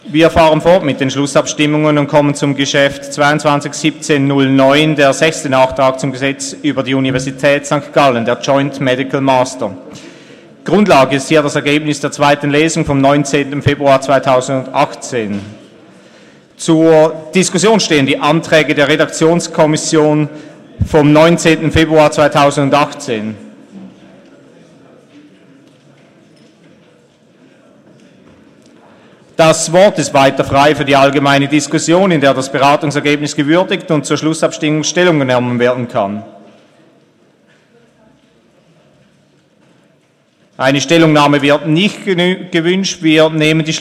Session des Kantonsrates vom 19. und 20. Februar 2018